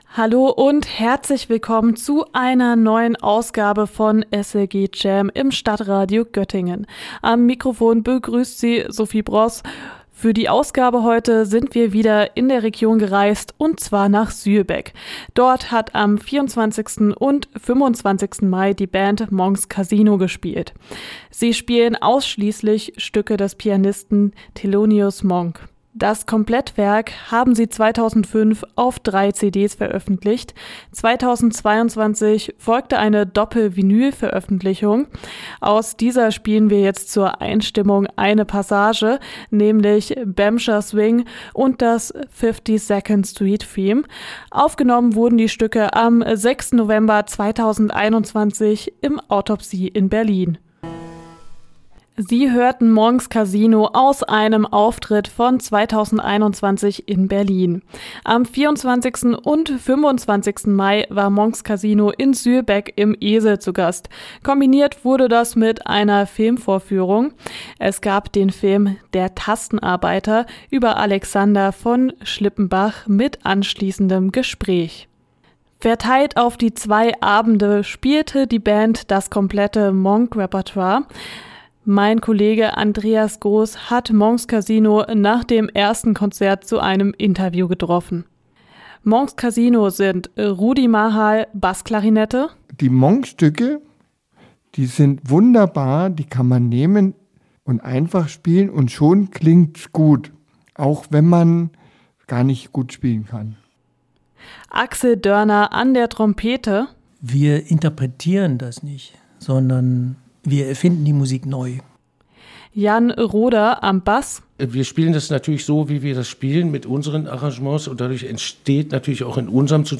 Thelonius Monk komplett: Interview mit der Jazzband Monk's Casino